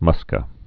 (mŭskə)